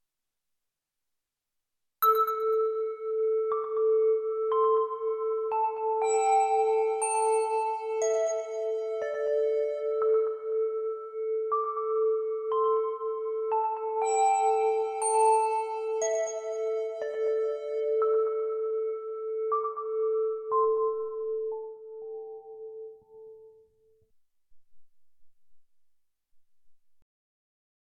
60 BPM
Lullaby — calm beasts, rest party
Descending minor pentatonic on harp-bell + warm pad drone
calm-rest.mp3